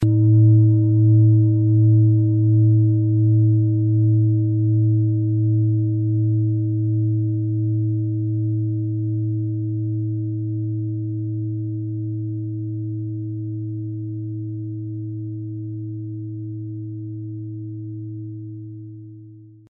Planetenton
Thetawelle
Im Sound-Player - Jetzt reinhören können Sie den Original-Ton genau dieser Schale anhören.
Durch die traditionsreiche Fertigung hat die Schale vielmehr diesen kraftvollen Ton und das tiefe, innere Berühren der traditionellen Handarbeit
MaterialBronze